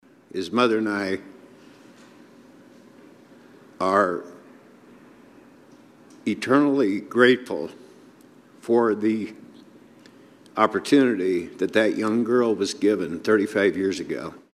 Senator Ken Rozenboom, a Republican from Oskaloosa, says he’s the proud father of a son who was given up for adoption by a 16-year-old who got support from this kind of an organization in Kansas City.